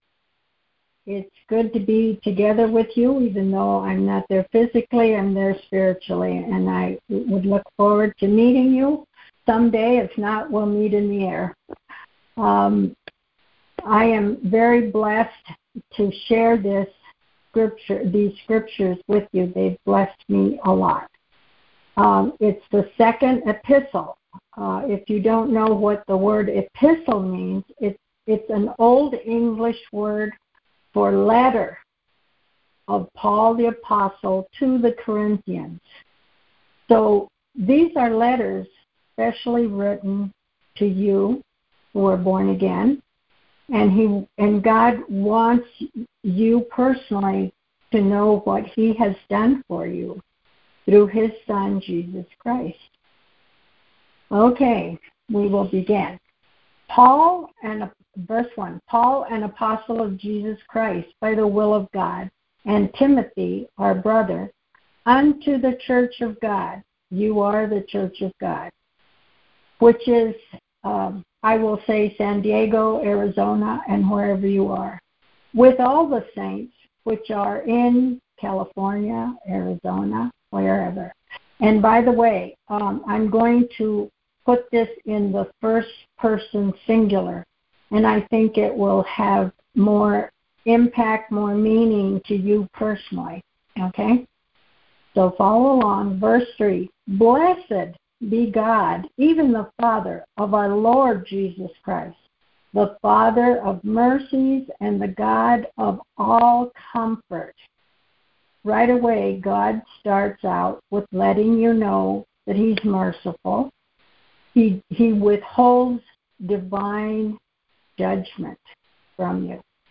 God is Yea and Amen Details Series: Conference Call Fellowship Date: Wednesday, 02 April 2025 Hits: 551 Scripture: 2 Corinthians 1:1-24 Play the sermon Download Audio ( 11.76 MB )